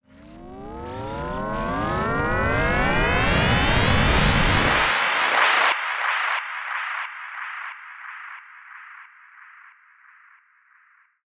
FX [ Transistor Transition ].wav